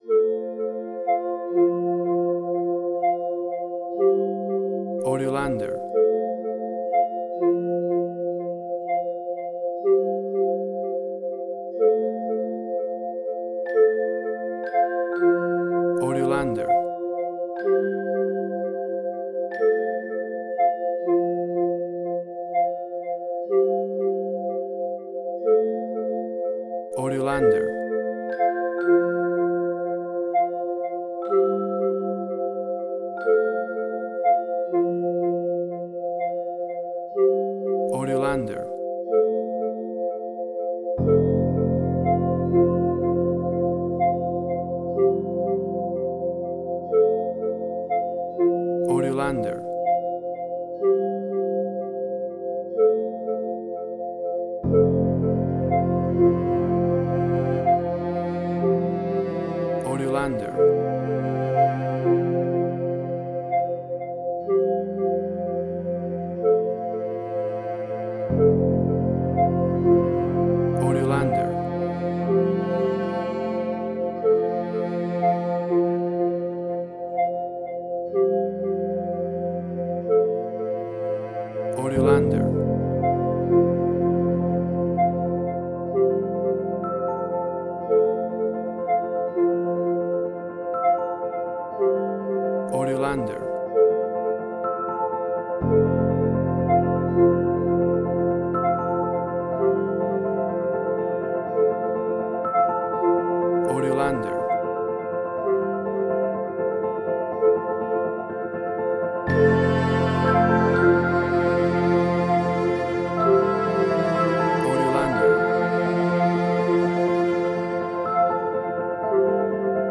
Spaghetti Western
Tempo (BPM): 62